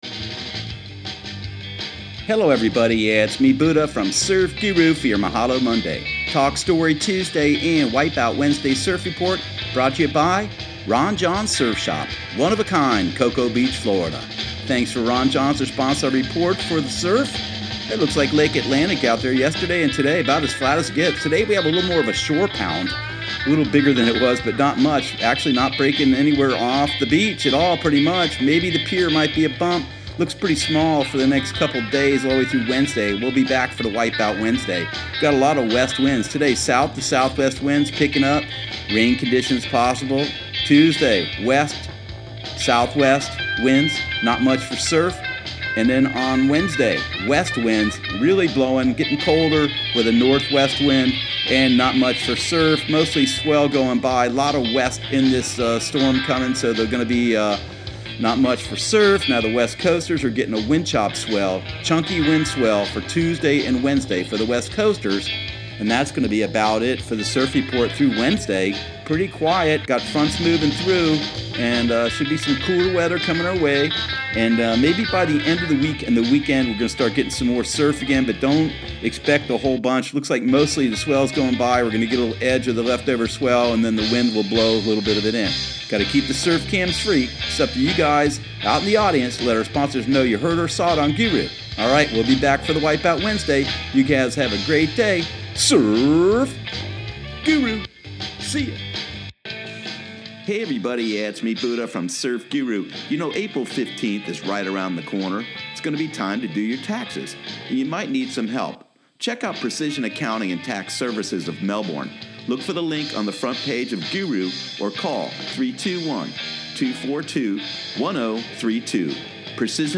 Surf Guru Surf Report and Forecast 03/19/2018 Audio surf report and surf forecast on March 19 for Central Florida and the Southeast.